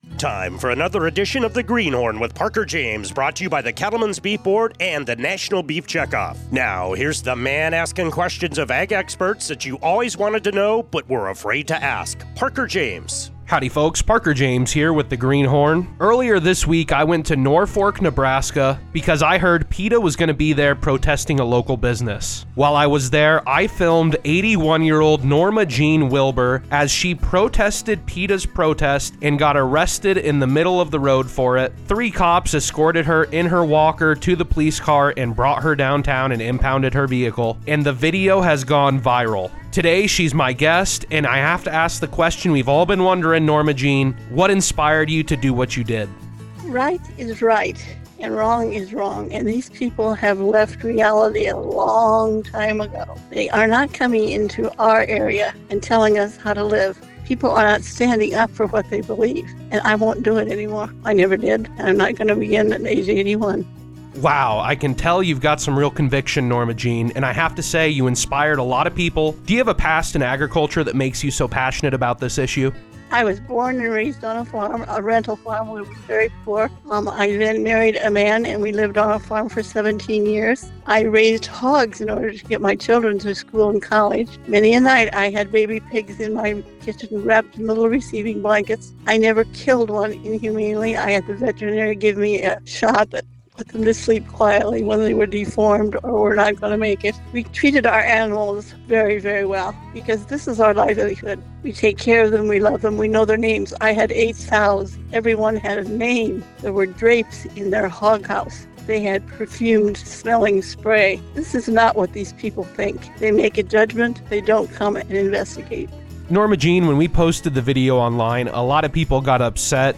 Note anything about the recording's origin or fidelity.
Now airing on Sirius XM 147 “Rural Radio”